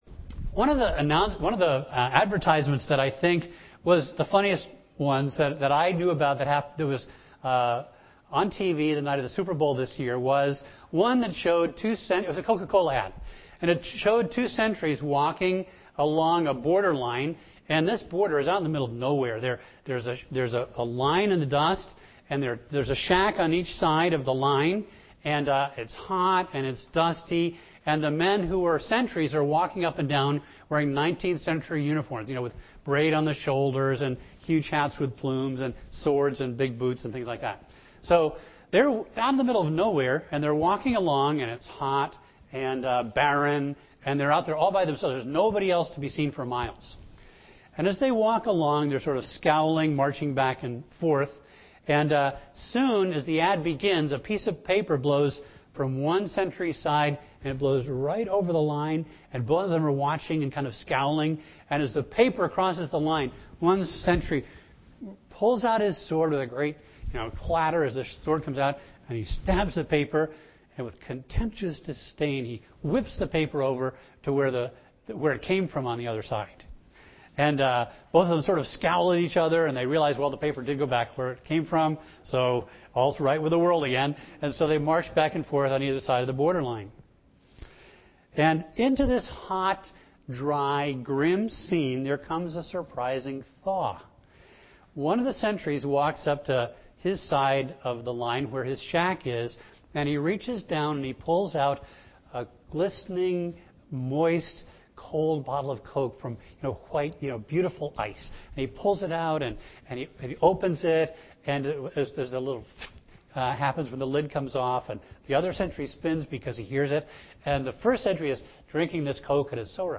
A message from the series "Gospel of Mark."